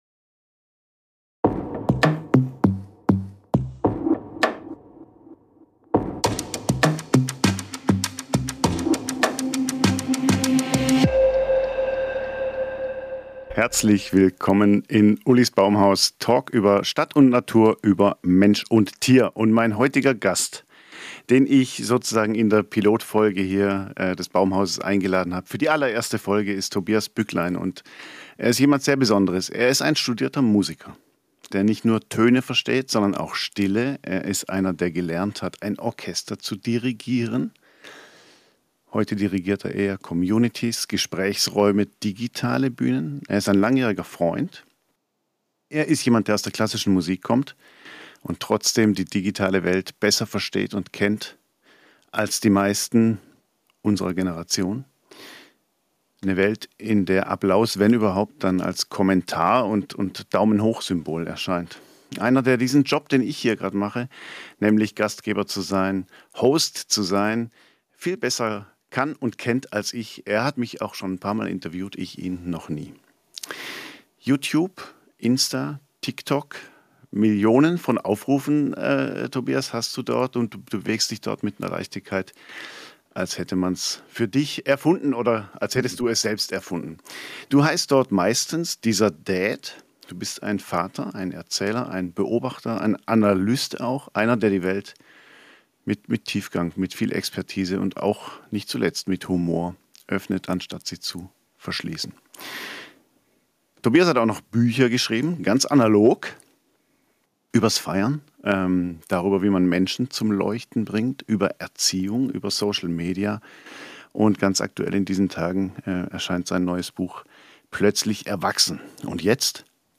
Ein Gespräch über Stadt und Seele, Gemeinschaft und Verantwortung, digitale Räume und reale Nähe.